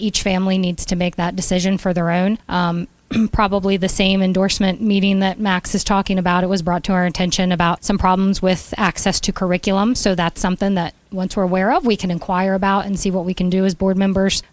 WCBC News hosted a forum for candidates running for open seats on the Allegany County Board of Education this week.
Incumbent candidate Chrystal Bender spoke about curriculum …